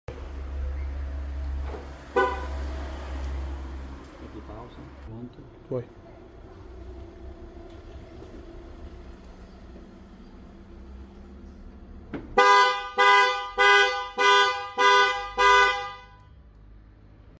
MERCEDES GLA con attivazione allarme sound effects free download
MERCEDES GLA con attivazione allarme originale